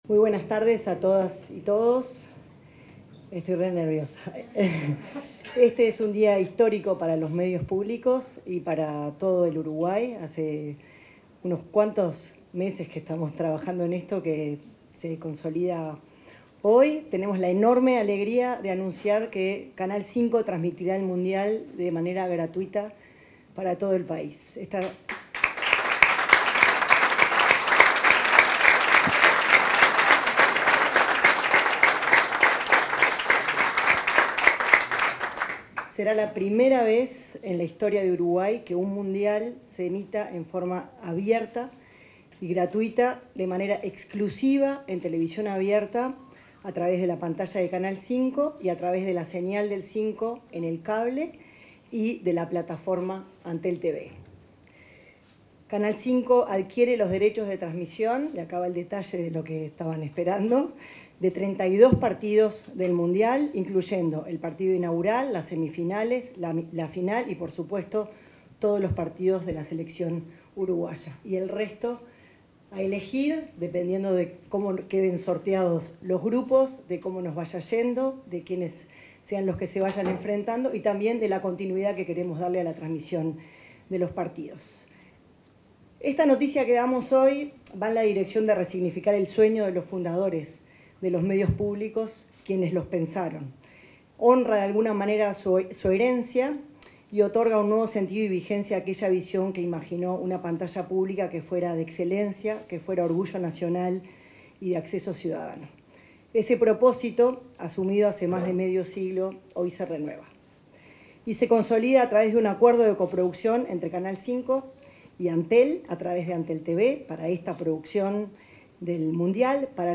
Palabras de autoridades en la presentación de la transmisión del Mundial FIFA 2026